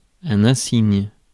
Ääntäminen
Ääntäminen France: IPA: /ɛ̃.siɲ/ Haettu sana löytyi näillä lähdekielillä: ranska Käännös Ääninäyte Substantiivit 1. insignia US 2. badge Adjektiivit 3. remarkable US Suku: m .